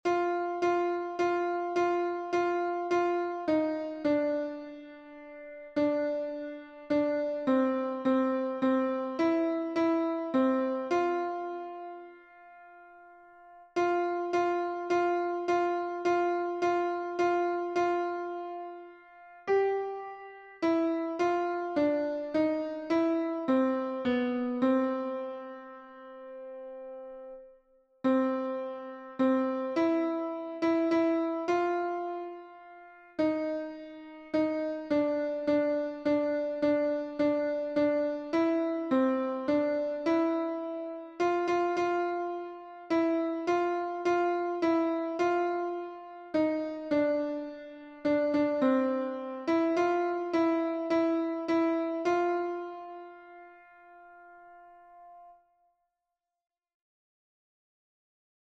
Turn Your Eyes upon Jesus — Alto Audio.
Words and music by Helen H. Lemmel, 1922 Tune: LEMMEL Key signature: F major (1 flat)
Turn_Your_Eyes_Upon_Jesus_alto.mp3